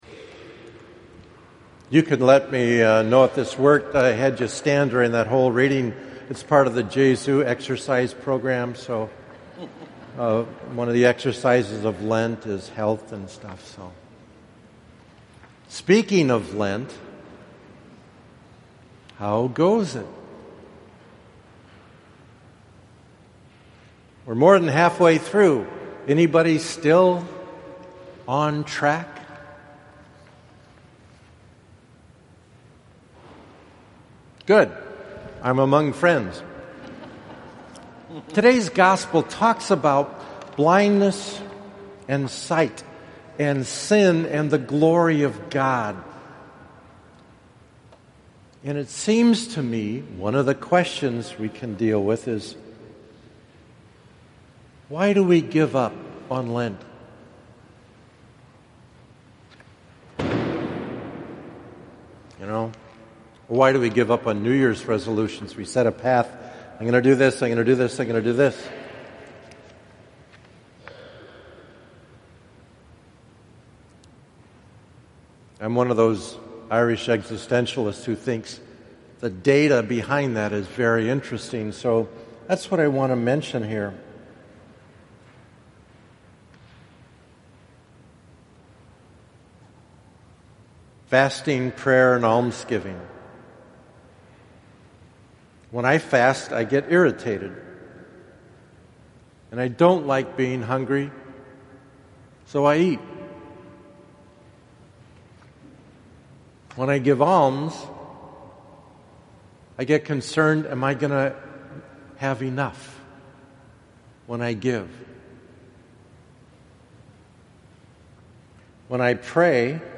4th SUN LENT – Lenten Blindness / Homily Audio